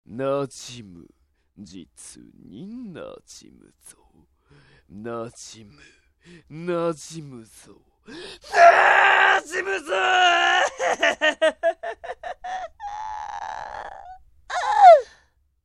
声真似奥義演習場~その弐~
声マネに関しては似てるモン似てねぇモン多々あります。